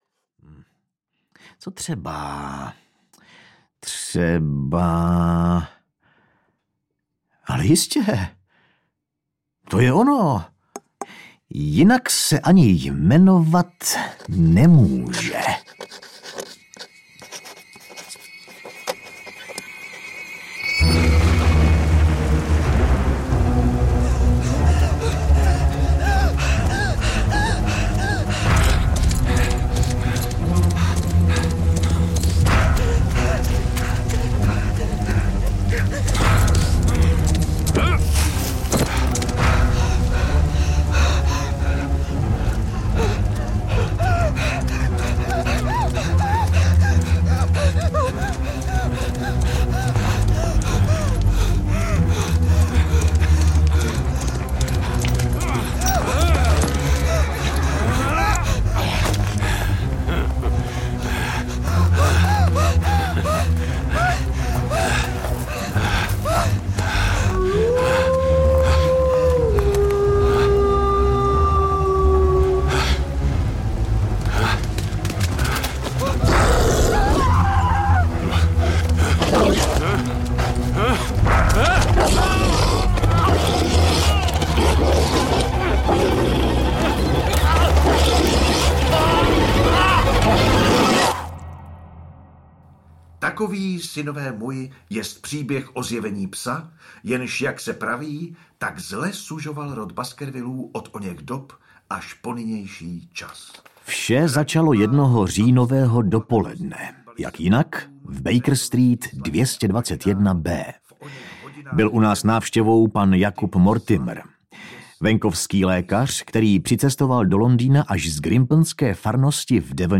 Audiokniha Pes baskervillský, kterou napsal Arthur Conan Doyle. Kdo po záhadné smrti sira Charlese usiluje o život jeho mladého dědice?
Ukázka z knihy